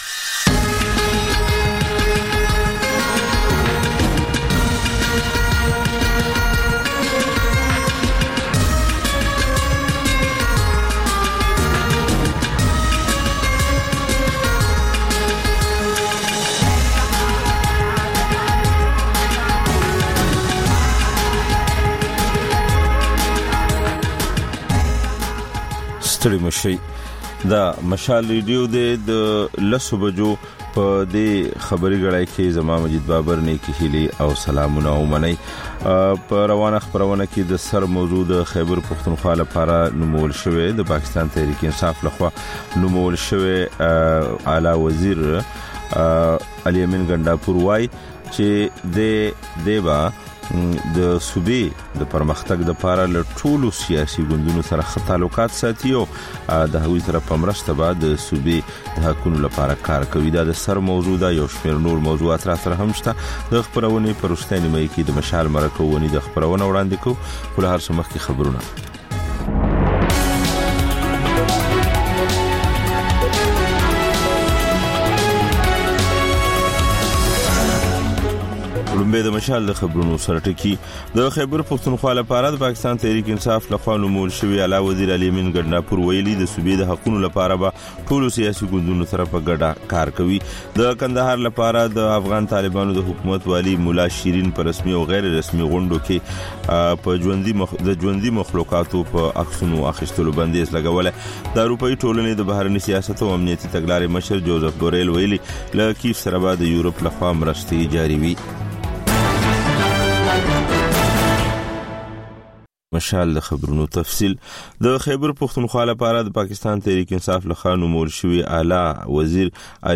په دې خپرونه کې تر خبرونو وروسته بېلا بېل رپورټونه، شننې او تبصرې اورېدای شﺉ. د خپرونې په وروستیو پینځلسو دقیقو یا منټو کې یوه ځانګړې خپرونه خپرېږي.